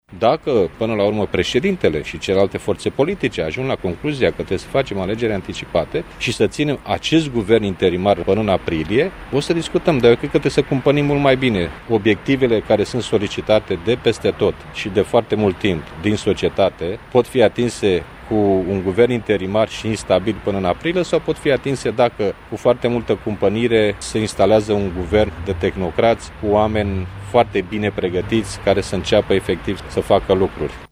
Liviu Dragnea a precizat că alegerile anticipate ar putea fi organizate, cel mai devreme, la începutul lunii aprilie: